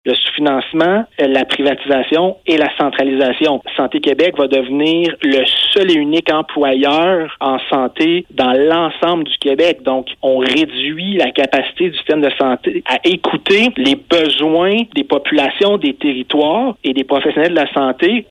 La nouvelle Coalition solidarité Outaouais a exprimé son désaccord et sa méfiance face aux dernières décisions prisent par le gouvernement du Québec, lors d’une conférence de presse qui s’est tenue en début de semaine. Québec a annoncé des coupures de 90 millions $ dans le secteur de la santé en Outaouais.